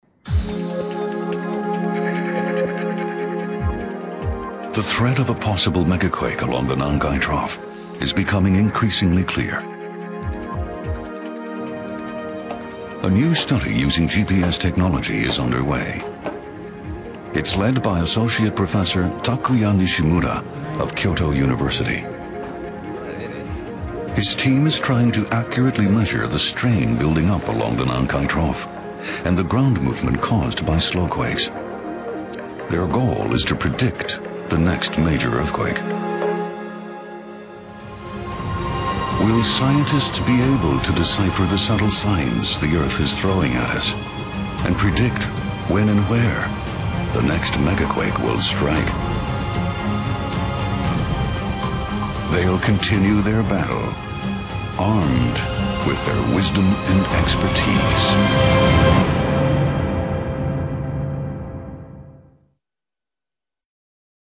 English inflection: Neutral North American
Tone: Baritone